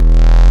Desecrated bass hit 16.wav